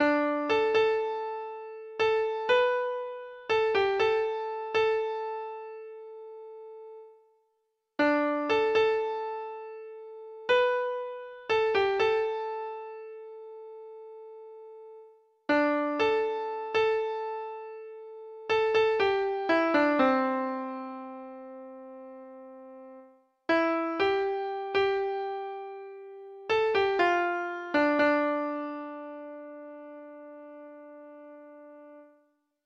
Traditional Trad. John Reilly Treble Clef Instrument version
Folk Songs from 'Digital Tradition' Letter J John Reilly
Free Sheet music for Treble Clef Instrument